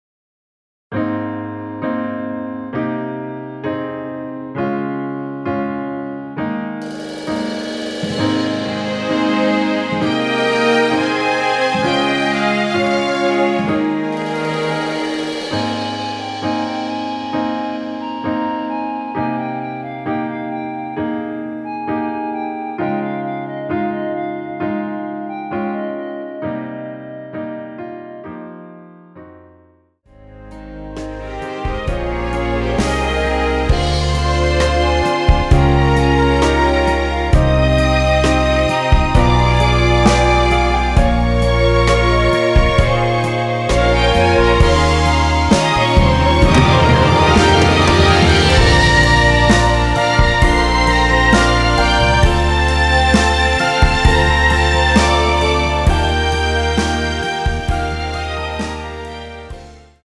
원키에서(+3)올린 멜로디 포함된 MR 입니다.(미리듣기 참조)
G#
앞부분30초, 뒷부분30초씩 편집해서 올려 드리고 있습니다.